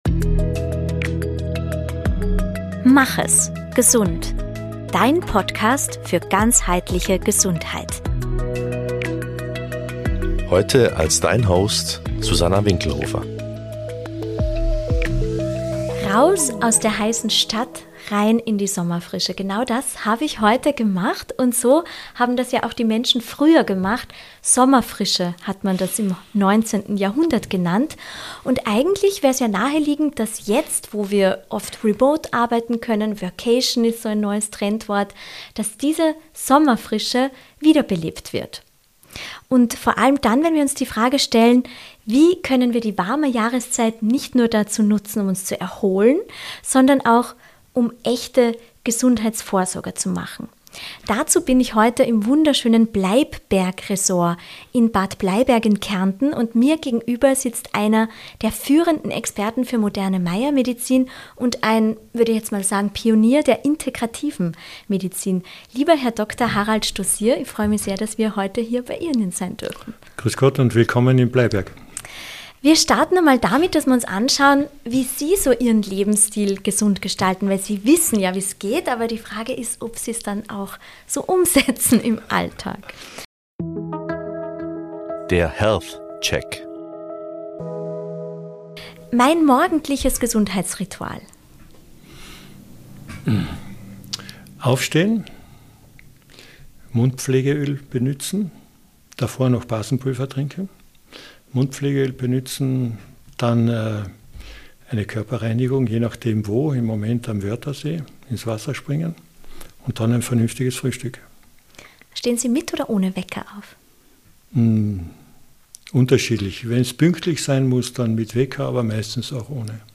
Und warum Regeneration kein Luxus, sondern biologische Notwendigkeit ist. Ein Gespräch wie eine Einladung zur Kurskorrektur – ruhig, klar, kompromisslos.